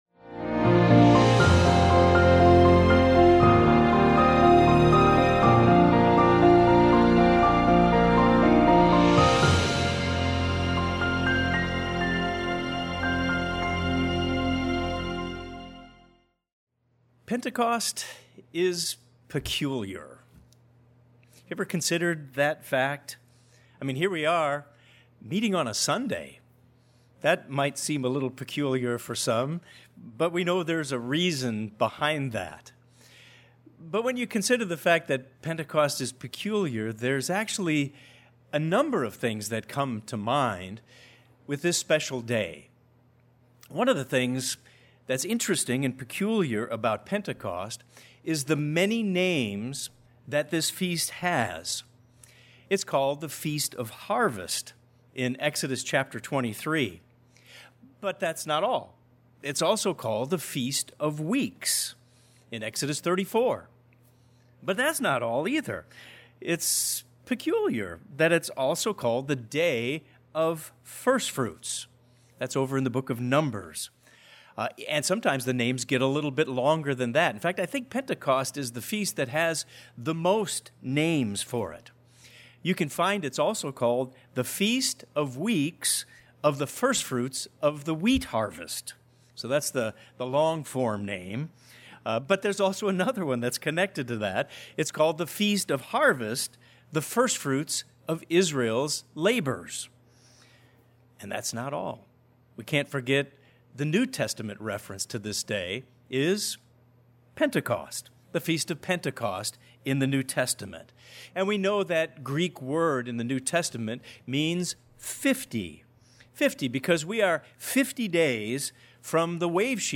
Peculiar can mean strange or odd, but that’s not all. This sermon discusses the uniqueness of Pentecost and how it should impact God’s peculiar people.